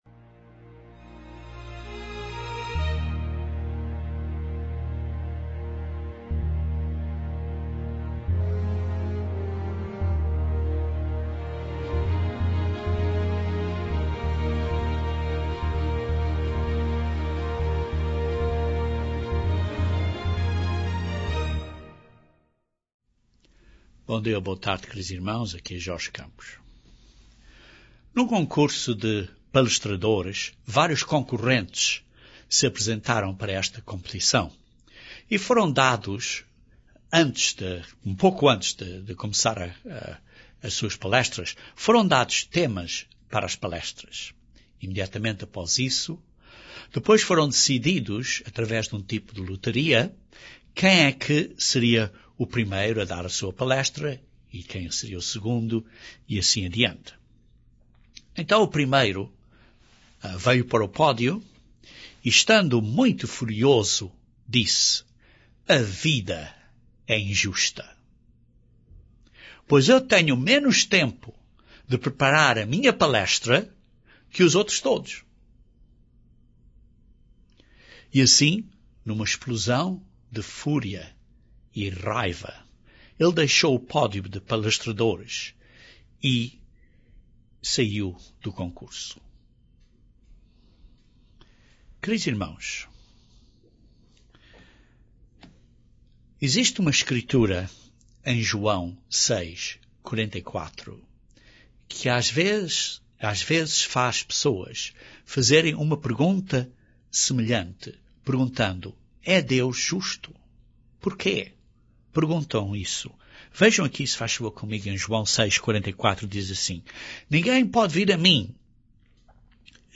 Por exemplo: Será Deus injusto, porque Ele só está chamando alguns agora? Este sermão explica por quê que Deus não é injusto com a Sua ordem de chamada e salvação.